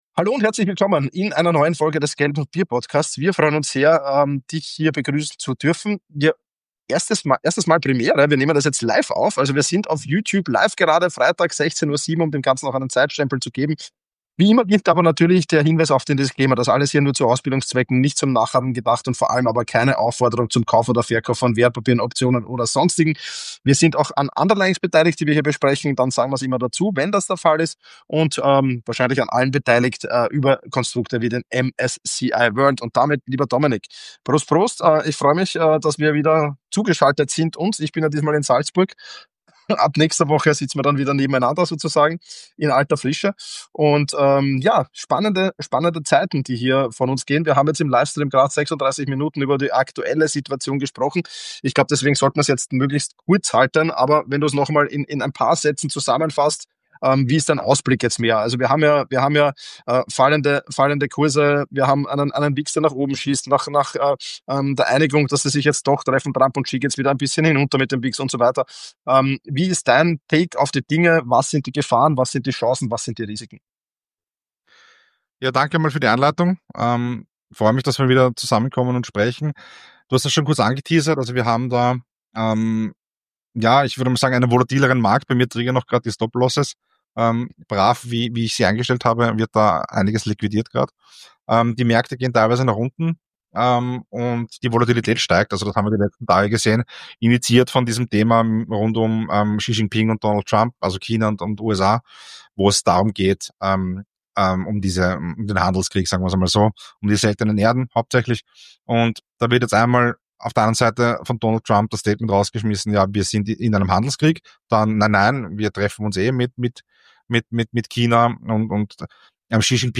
Live-Episode